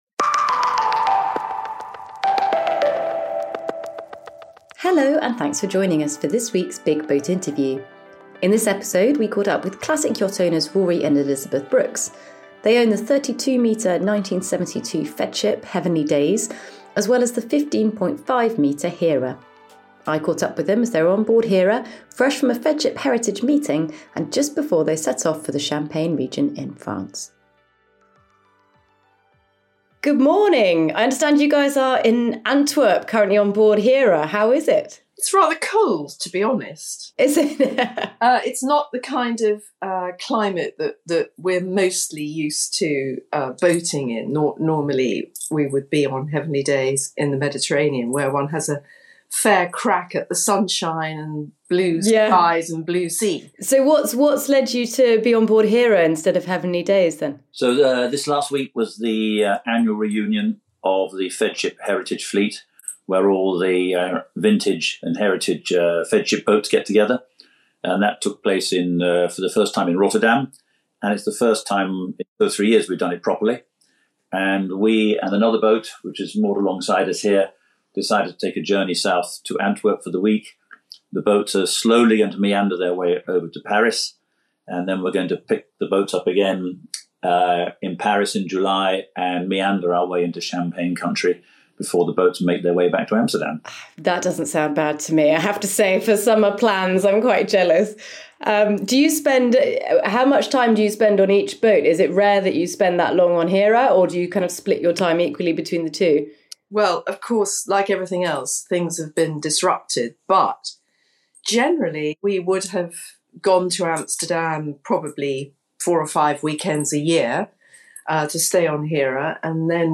The Big BOAT Interview